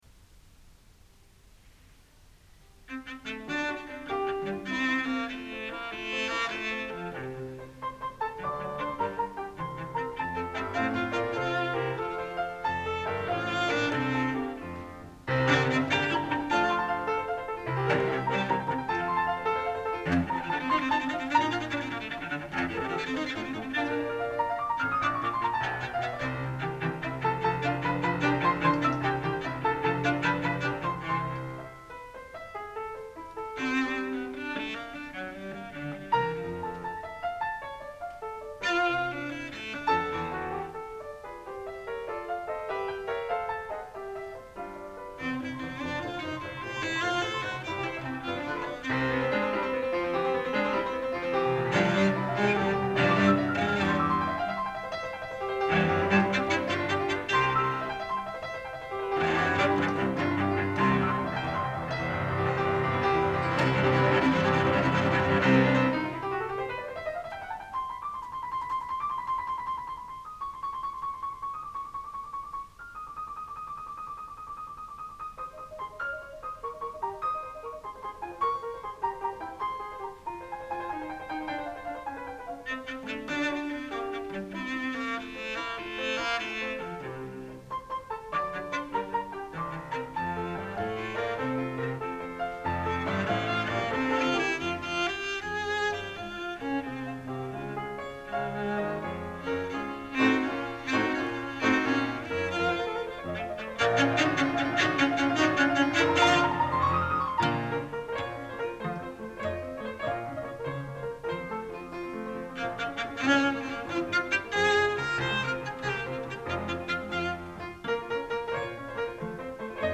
Recording of concert entitled Beethoven and His Time, I. Beethoven the Classicist. The concert opens with a performance of Beethoven's 12 Variations on a theme from "Judas Maccabaeus" by Handel, for cello and piano.
Cassette tape
Cello and piano music
Musical performance